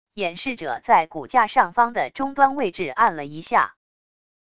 演示者在骨架上方的中端位置按了一下wav音频